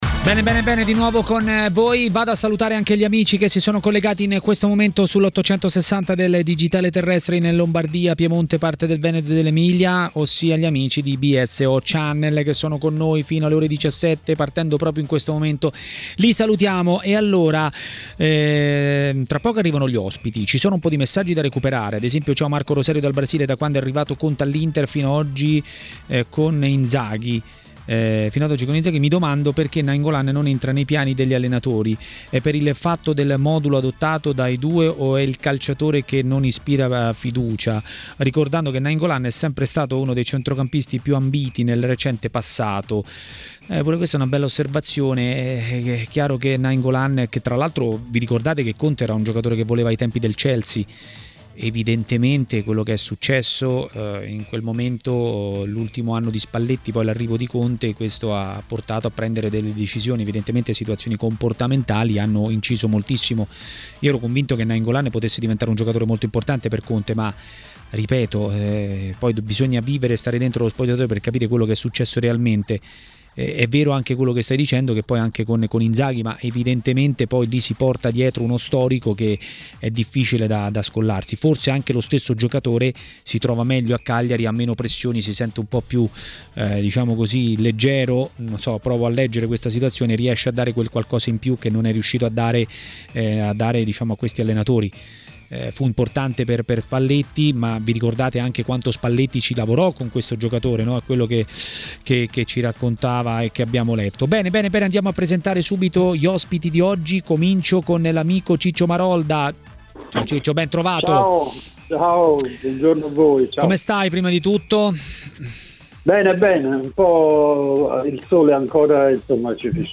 A parlare del Napoli di Luciano Spalletti a Maracanà, nel pomeriggio di TMW Radio, è stato l'ex calciatore e tecnico David Di Michele.